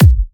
VFH2 129BPM House Of Love Kick.wav